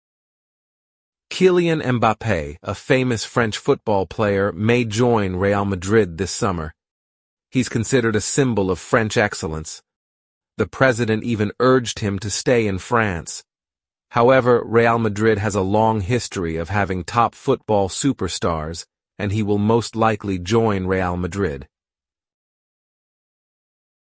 Other AI voices
Super Realistic AI Voices For Your Listening Practice